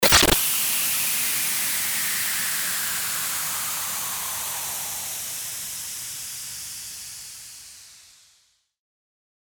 FX-1864-STARTER-WHOOSH
FX-1864-STARTER-WHOOSH.mp3